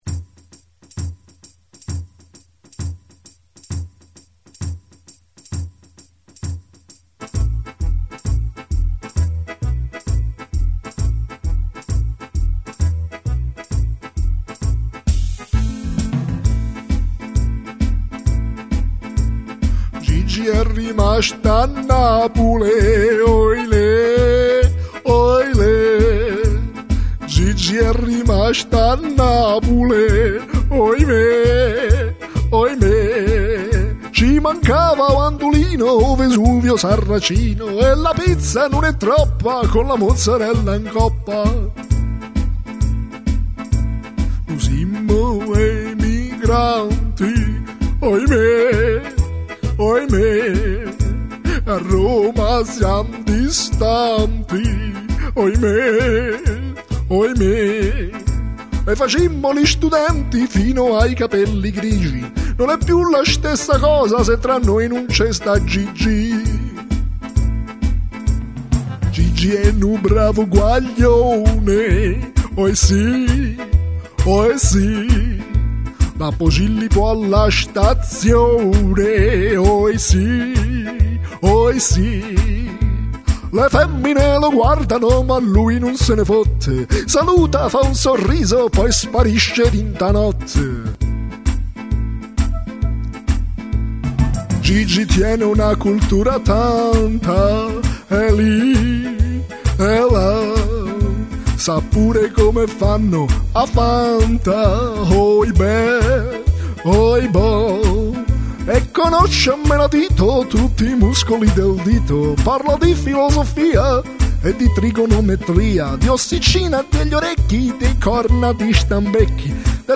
Una nostalgica tarantella per l'amico che e' rimasto a Napule, per i sempre piu' afflitti in affitto!